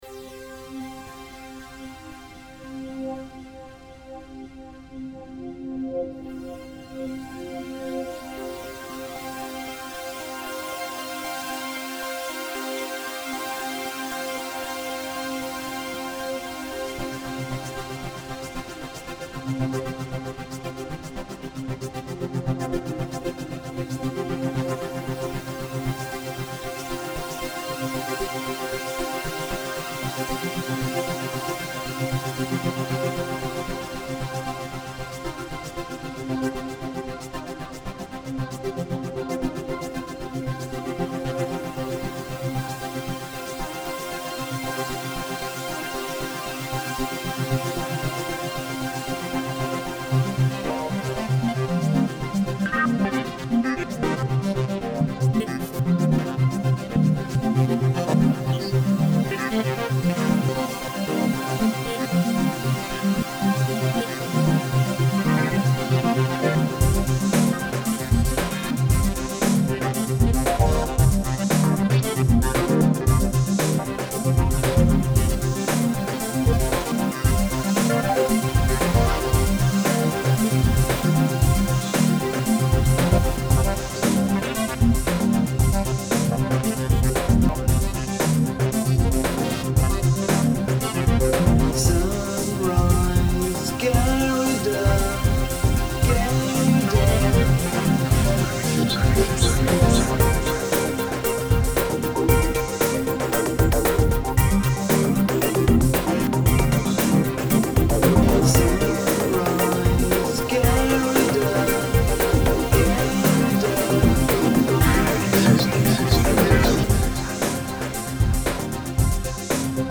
Enregistré et mixé à LGS Studio 1, BBA,